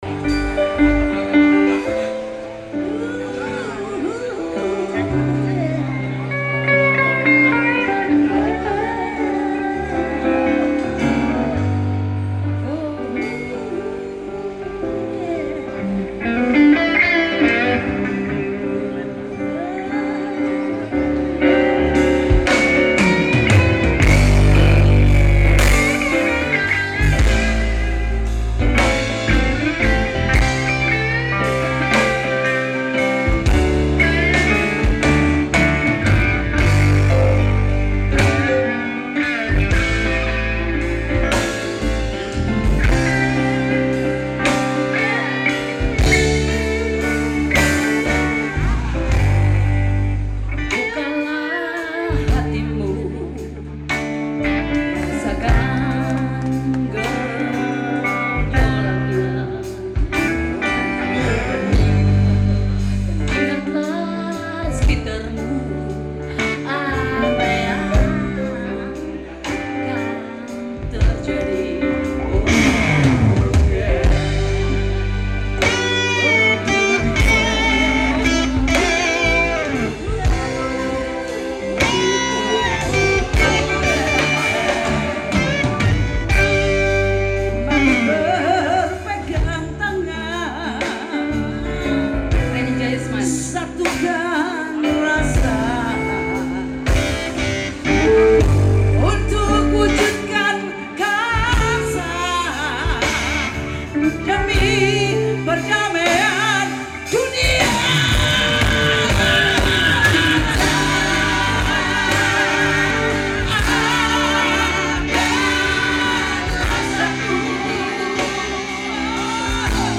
penyanyi rock